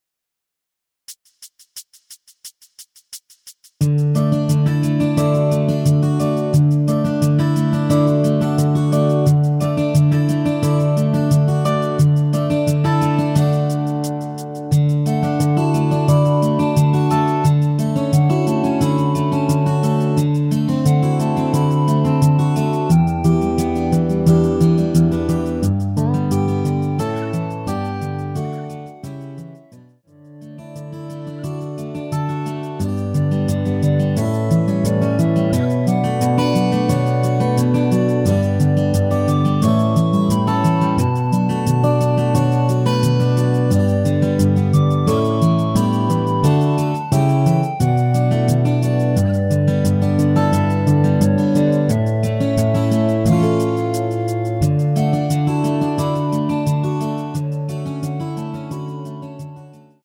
원키 멜로디 포함된 MR입니다.(미리듣기참조)
앞부분30초, 뒷부분30초씩 편집해서 올려 드리고 있습니다.
중간에 음이 끈어지고 다시 나오는 이유는
(멜로디 MR)은 가이드 멜로디가 포함된 MR 입니다.